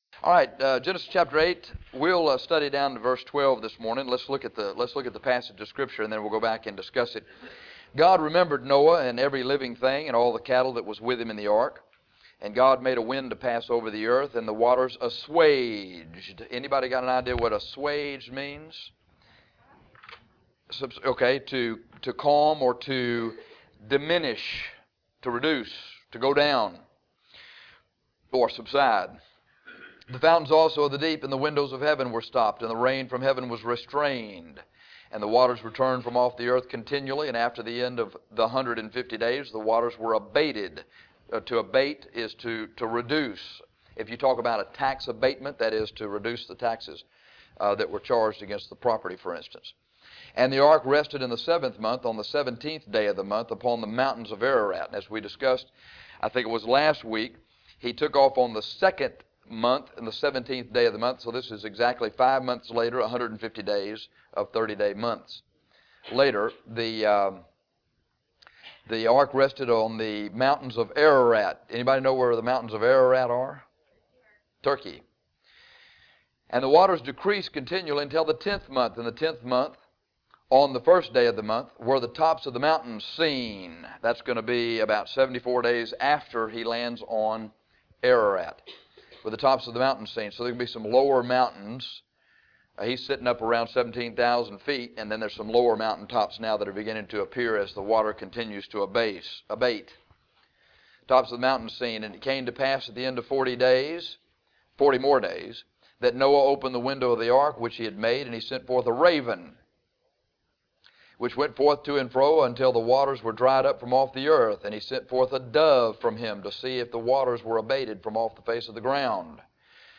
This lesson is about the Raven and the Dove sent by Noah at the end of the Flood.